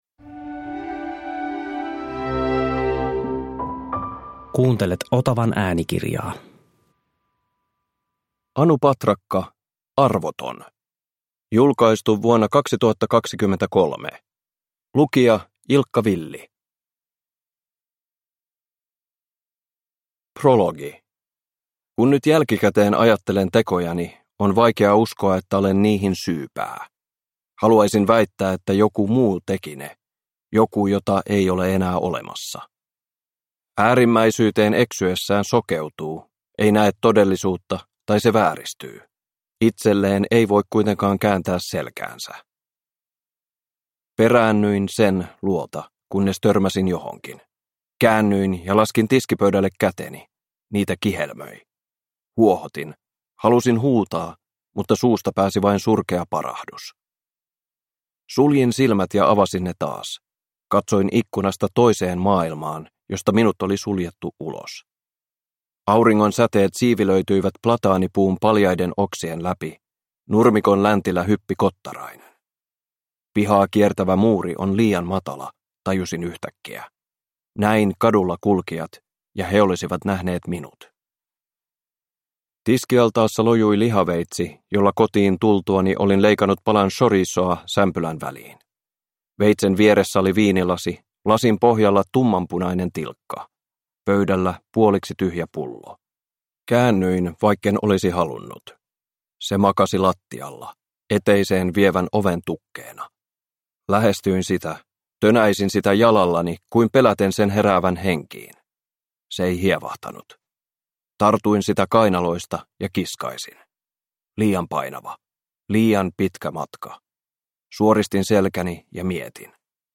Arvoton – Ljudbok – Laddas ner
Uppläsare: Ilkka Villi